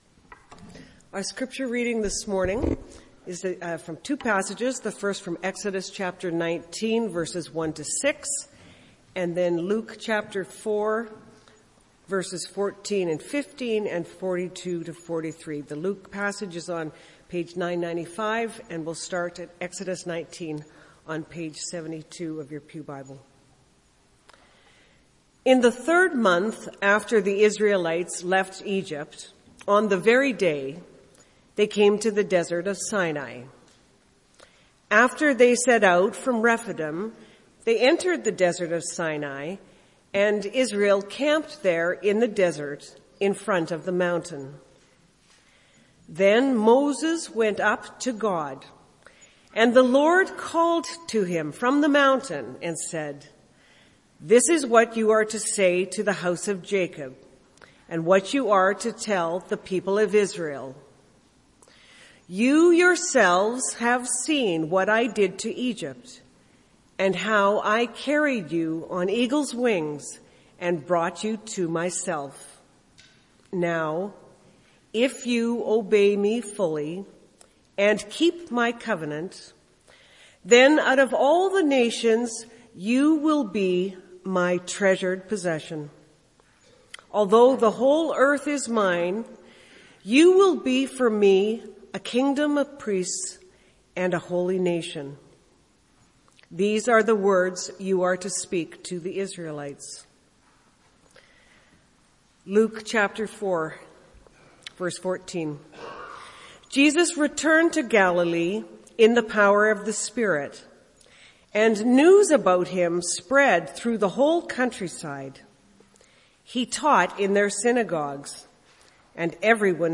MP3 File Size: 22.4 MB Listen to Sermon: Download/Play Sermon MP3